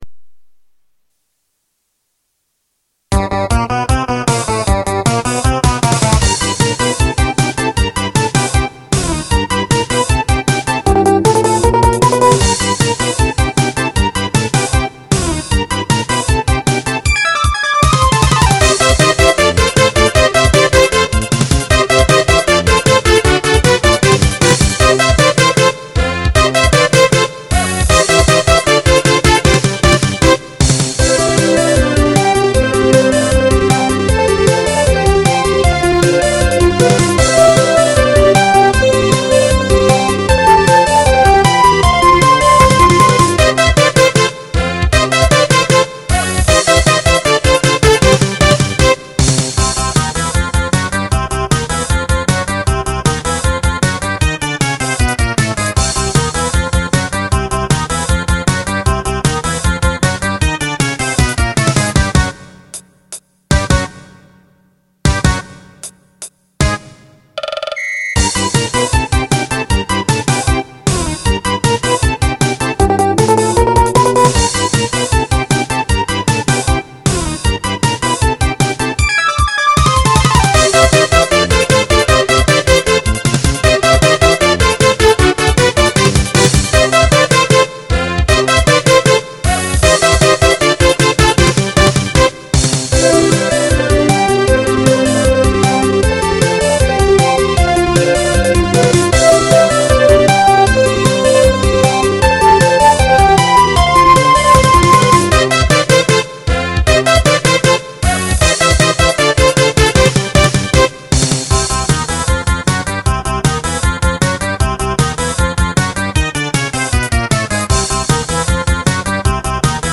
管理人が作ったMIDI集です
“もしもスーファミ版があったら・・・”って感じを目指しました。